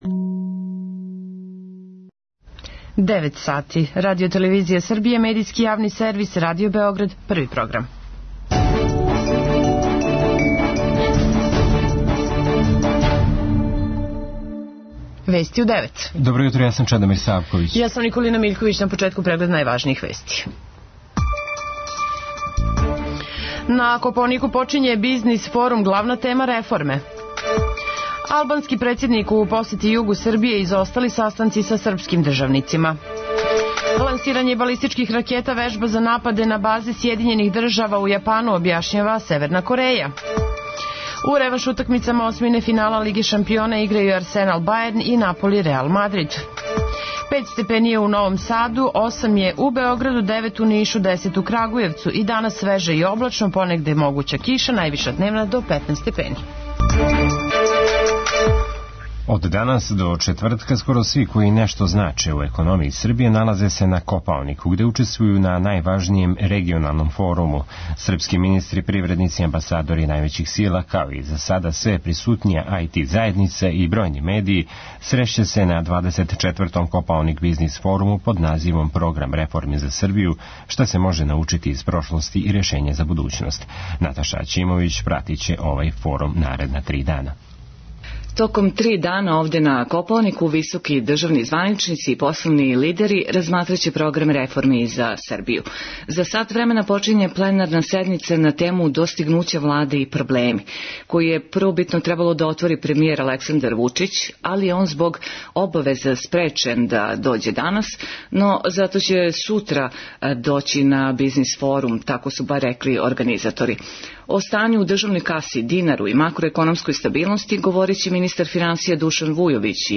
преузми : 3.59 MB Вести у 9 Autor: разни аутори Преглед најважнијиx информација из земље из света.